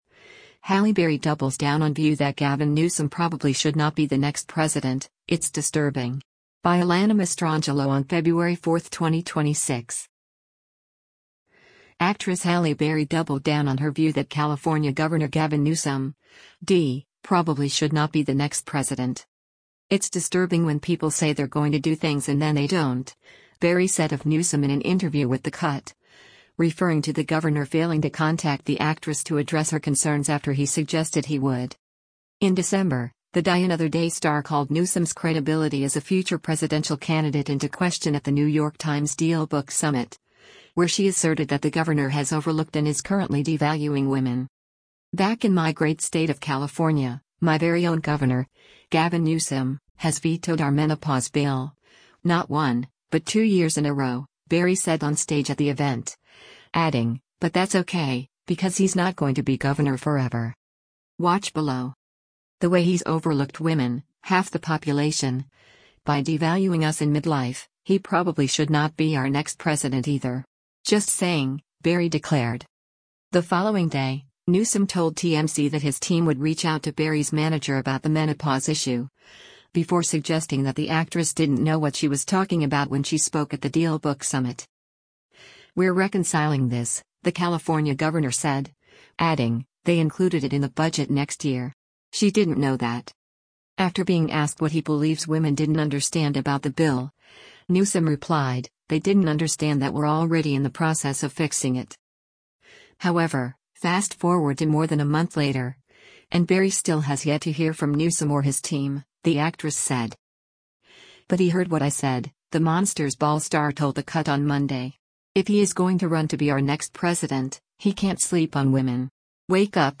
NEW YORK, NEW YORK - DECEMBER 03: Halle Berry speaks onstage during The New York Times Dea